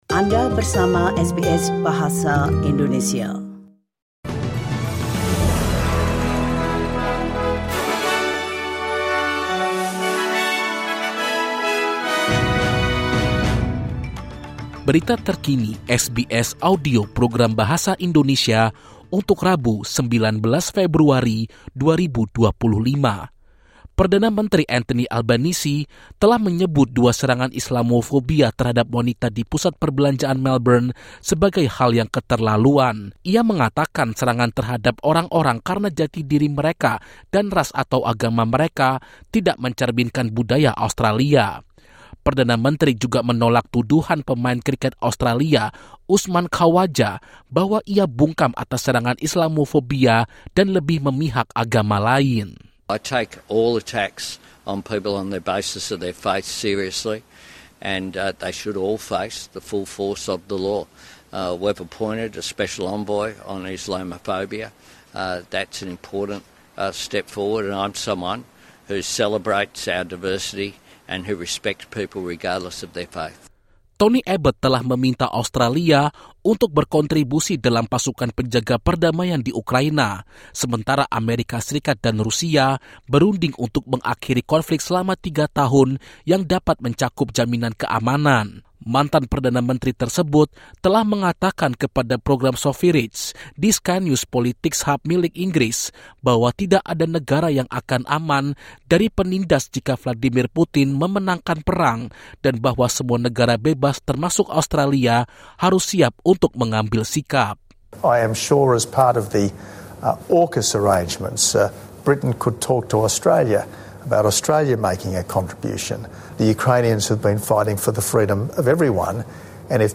Newsflash SBS Audio Program Bahasa Indonesia